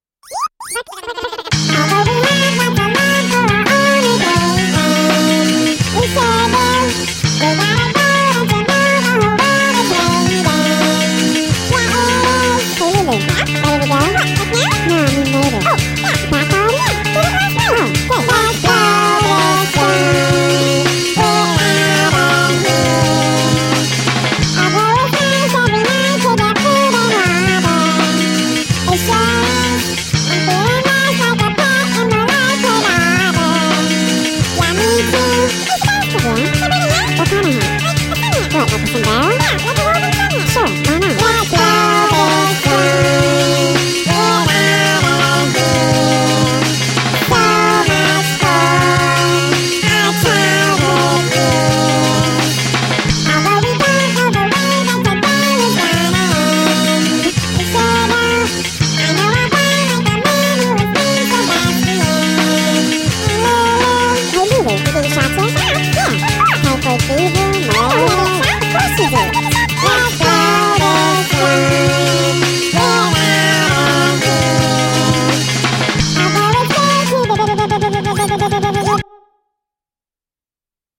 Unmastered.